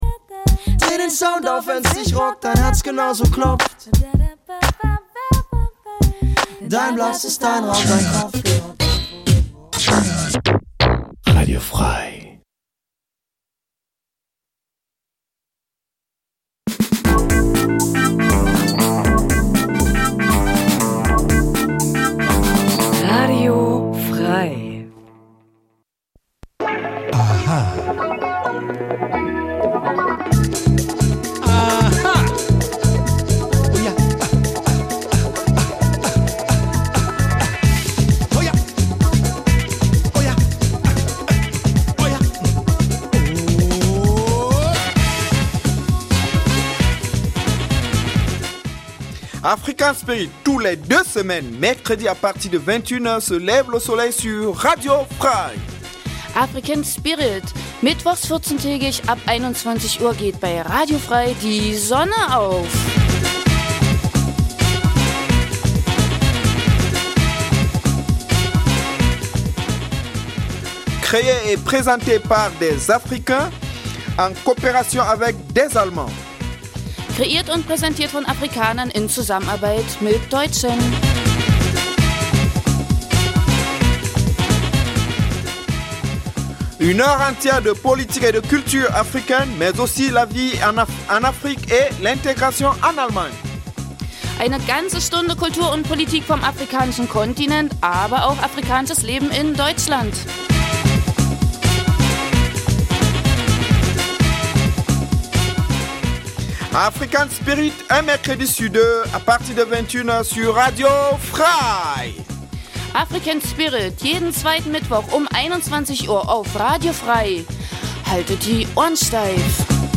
Neben aktuellen Nachrichten gibt es regelmäßig Studiogäste, Menschen die in irgendeiner Form etwas mit Afrika zu tun ha
Die Gespräche werden mit afrikanischer Musik begleitet.